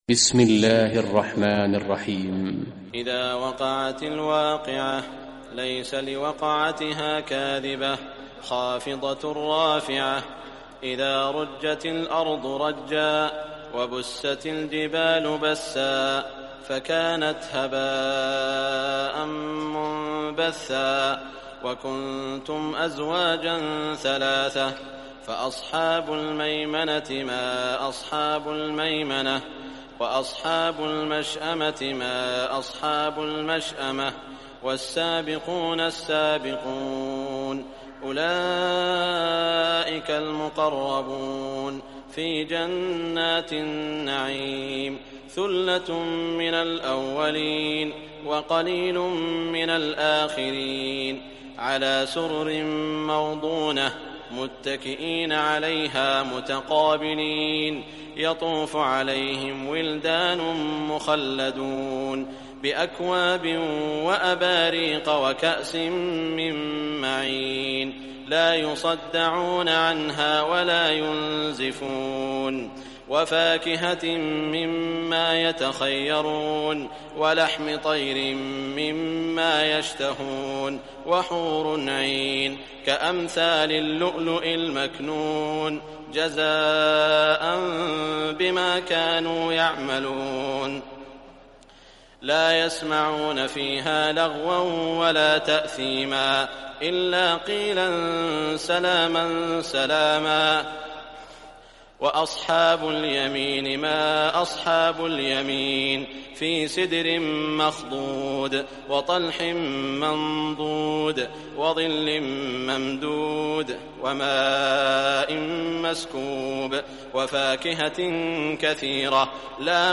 Surah Waqiah Recitation by Sheikh Saud Shuraim
Surah Waqiah, listen or download online mp3 tilawat of Surah Waqiah free mp3 in best audio quality. Listen beautiful recitation in the voice of Imam e Kaaba Sheikh Saud al Shuraim.